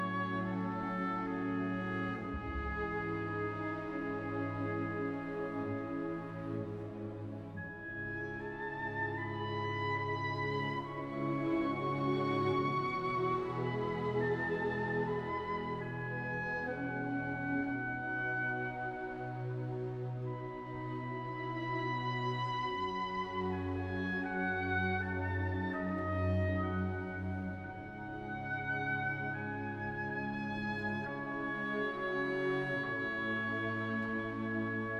# Classical